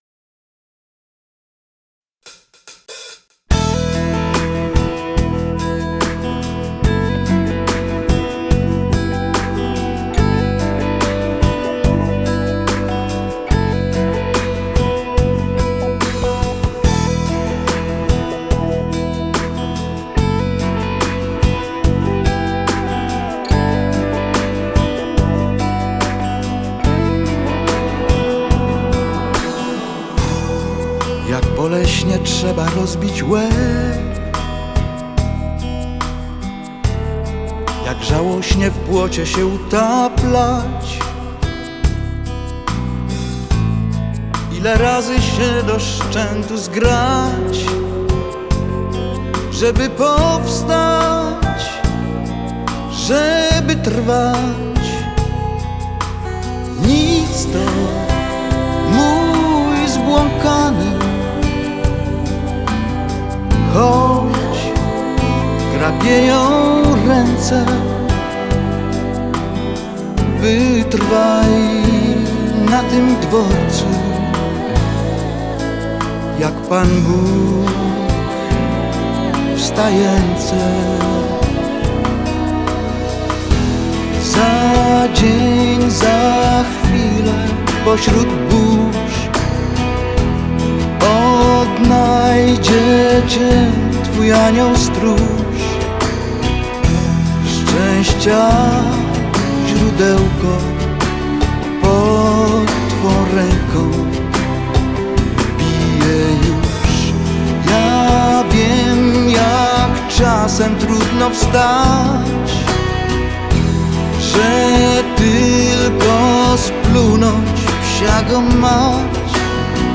мелодичных композиций